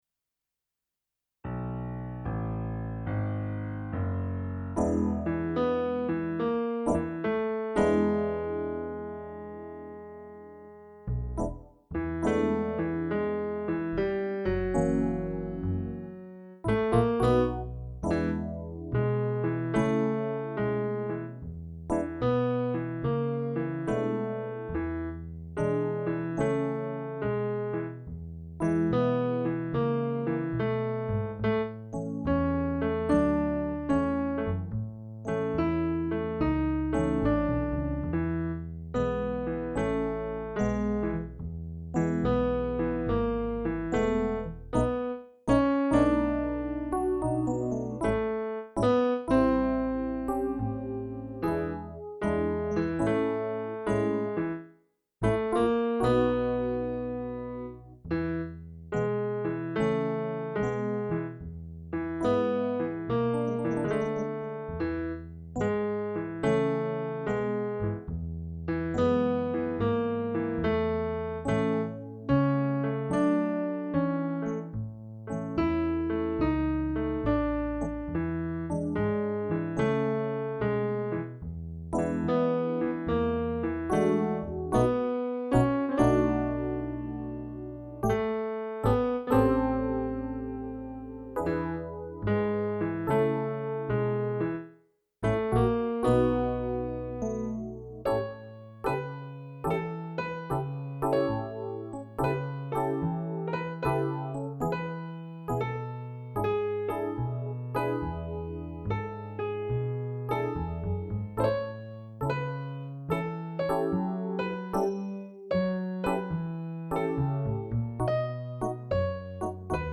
BLUES AND SOUL (Blues) HYMNE POUR LA PAIX (Slow) LA MARCHE D'UN ADIEU (Slow) ROSE DES SABLES (Slow) >>>>>- à voir sur-You Tube-